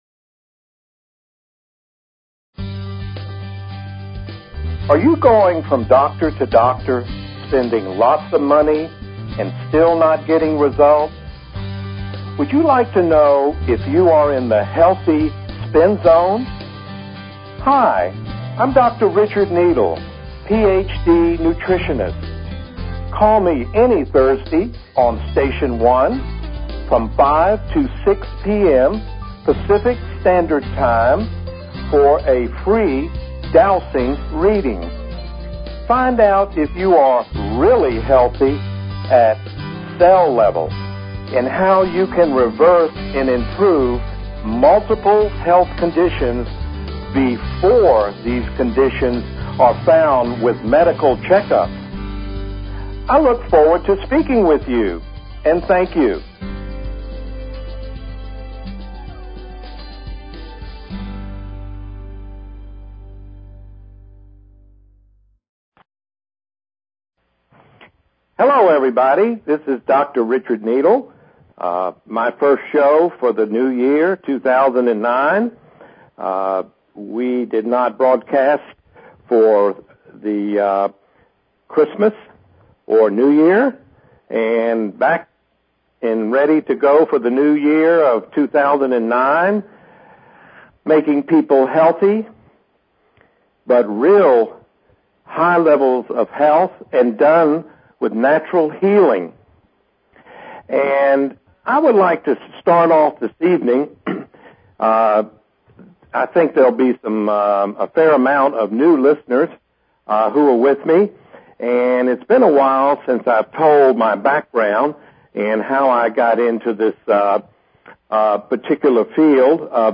Talk Show Episode, Audio Podcast, Dowsing_for_Health and Courtesy of BBS Radio on , show guests , about , categorized as